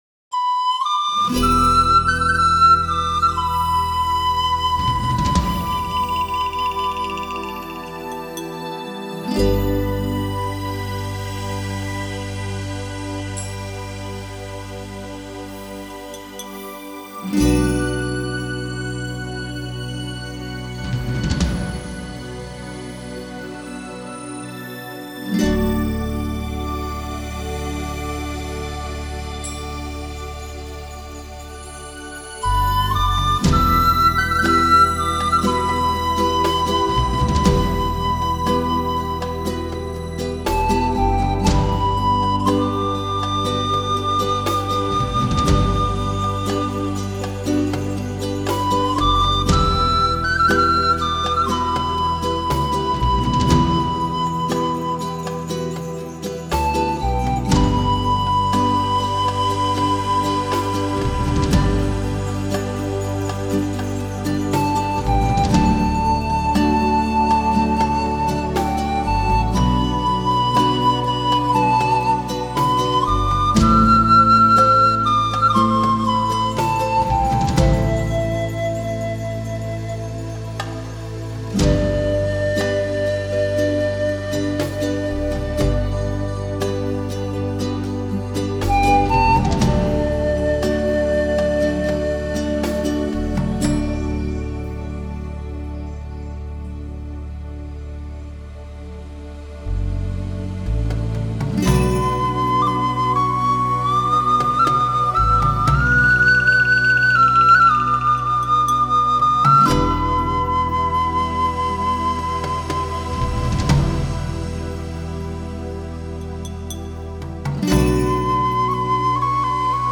【新世纪】
南美风情，南美音乐。